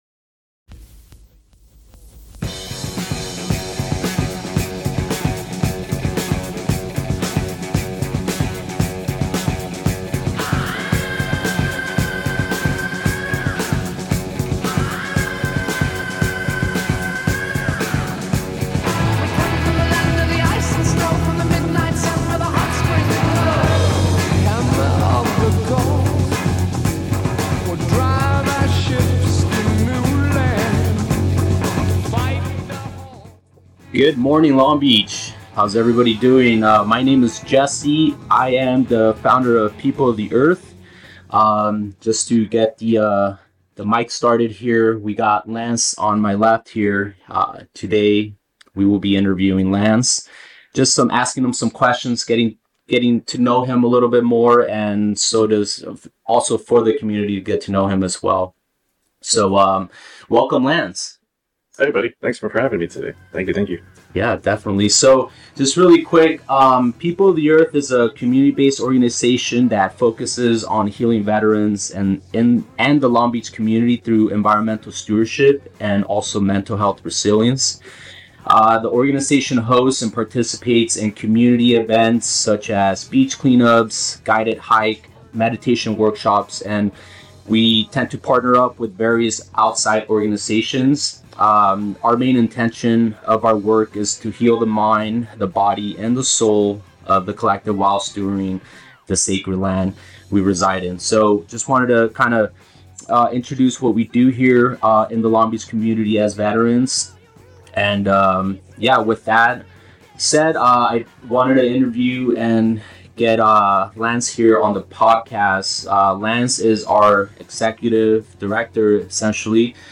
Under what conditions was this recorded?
This episode of POTE aired live on CityHeART Radio on Friday Sept. 1 at 11am(ish).